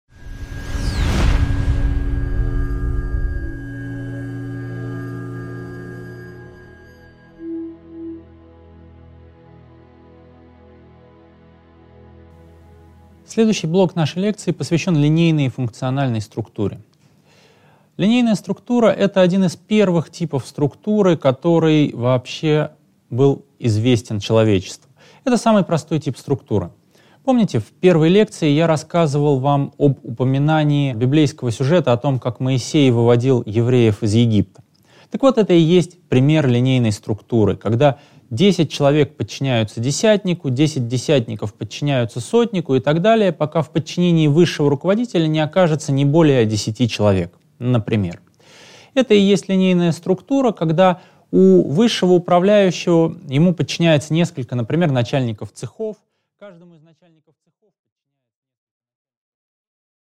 Аудиокнига 2.4. Линейная и линейно-функциональная структуры | Библиотека аудиокниг